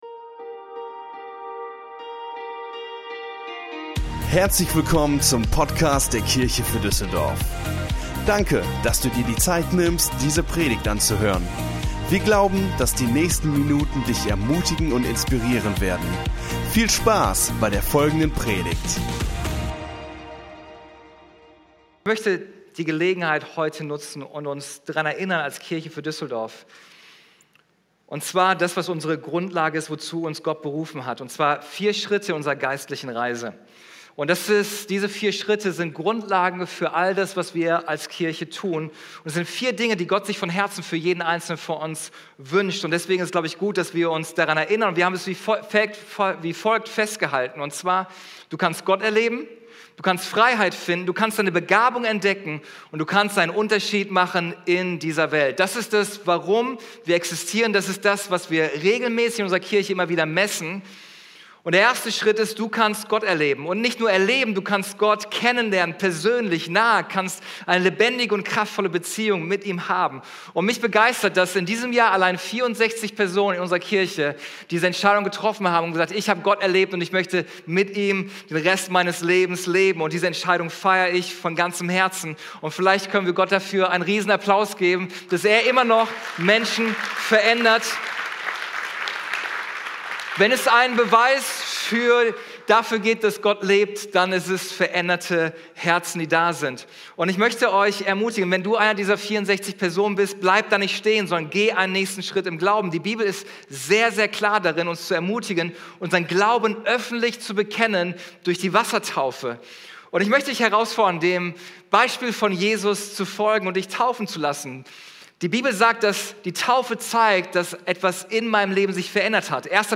Visionsgottesdienst